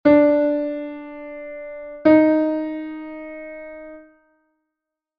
RE-MIb
semiton.mp3